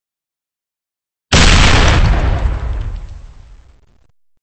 微型爆炸.MP3